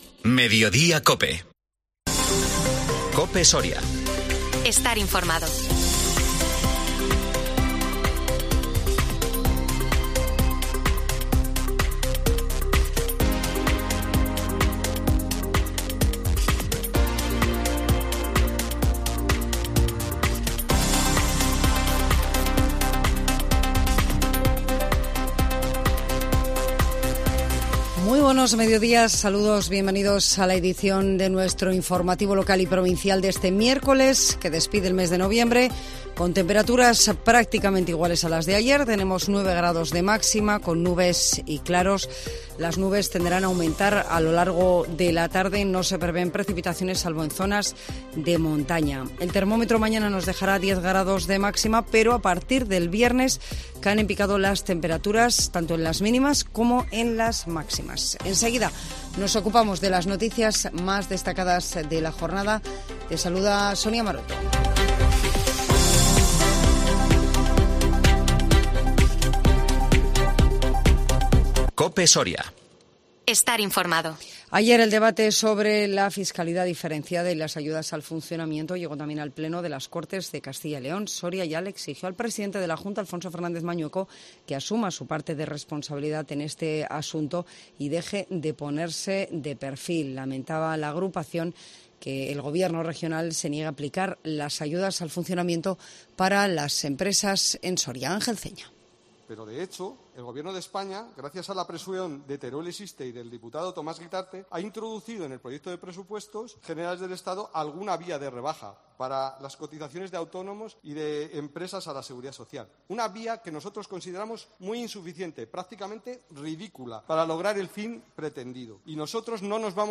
INFORMATIVO MEDIODÍA COPE SORIA 30 NOVIEMBRE 2022